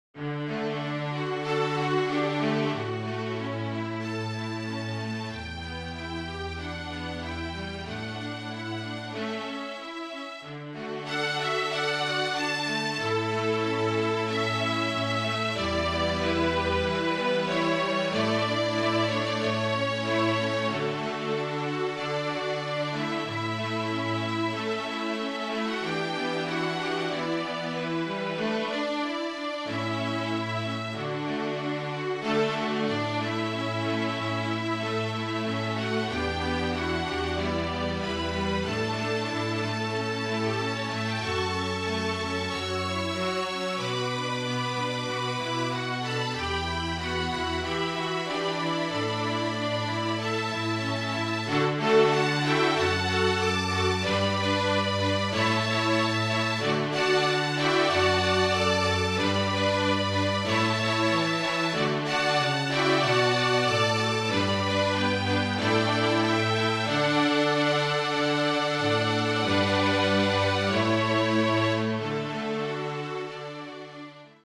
FLUTE QUARTET
(Flute, Violin, Viola and Cello)
JEWISH SONGS
MIDI